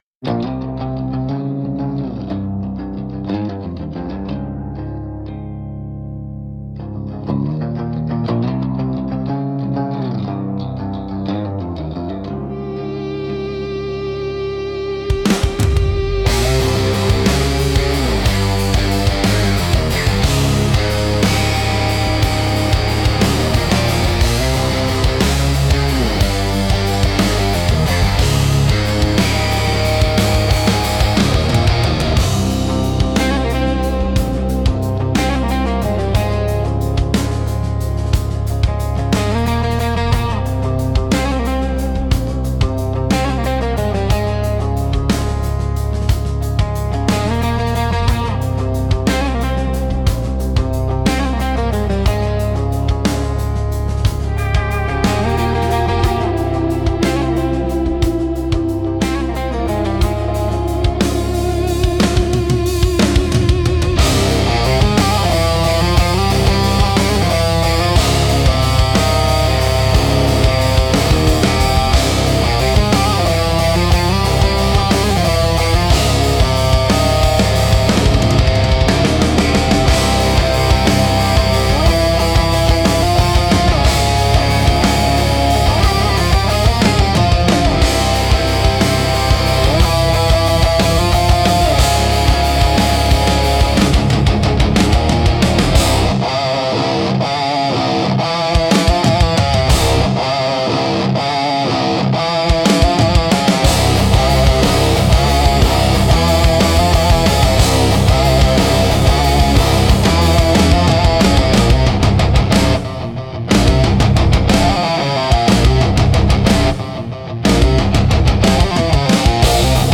Instrumental - Bleeding into Open E